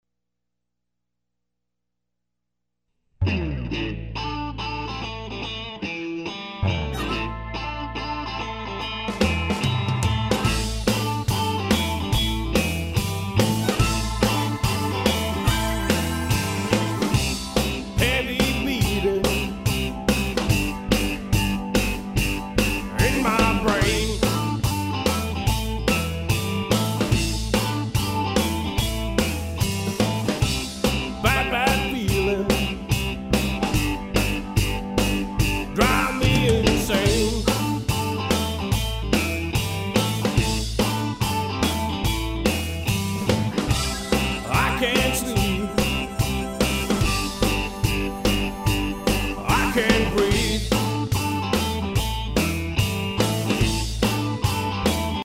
specialize in a hard-drivin', rockin' style of blues.
is a mix of powerful, down-home rock with elements
and recorded with us here at Oat Central in Nashville.